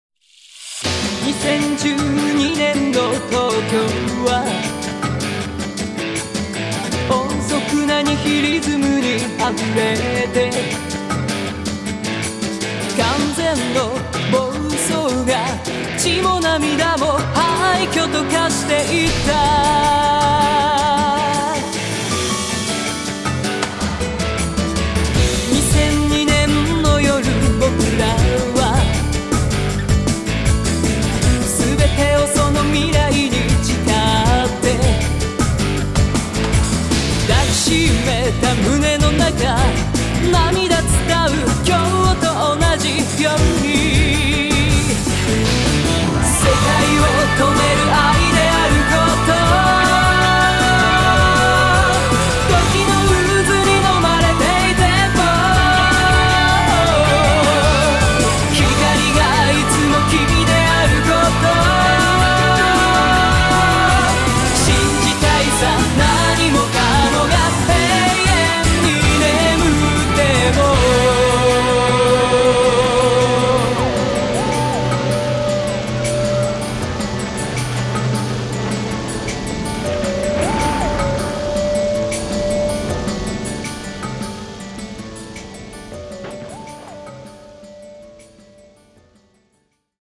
BPM158
A woman.